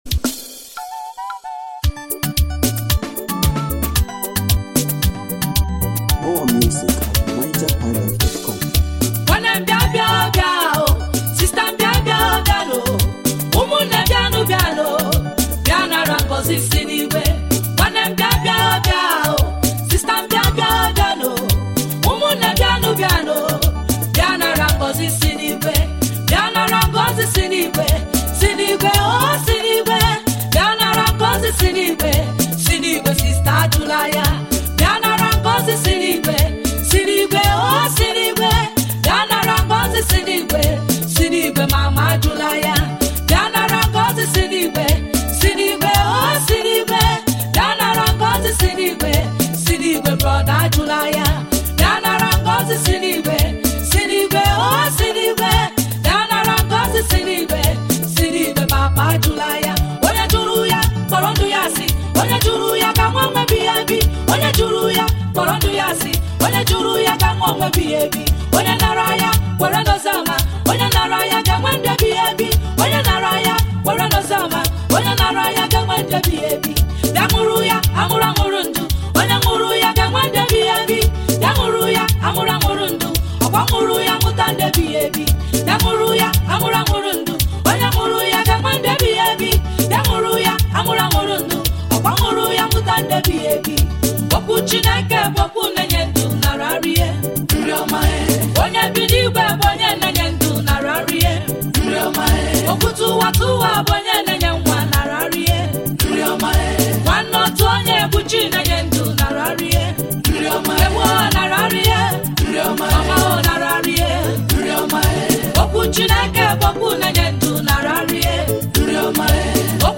Igbo Gospel music